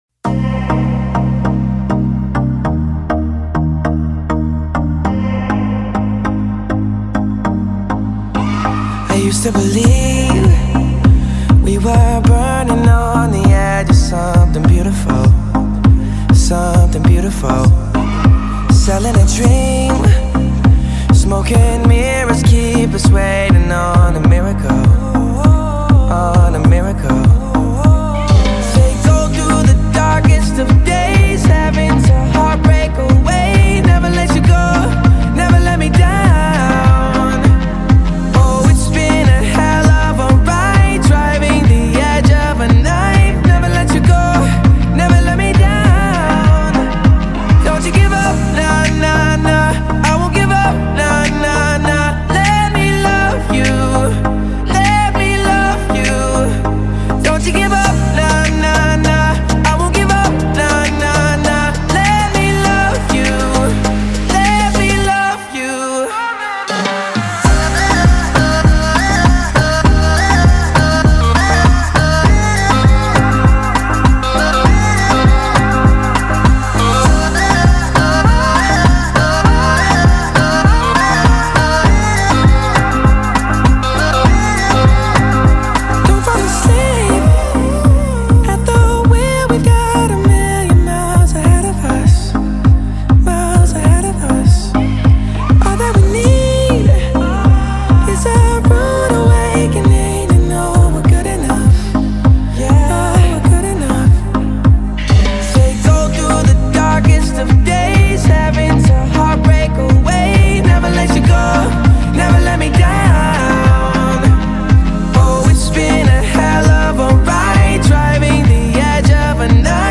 The song opens with a smooth, atmospheric production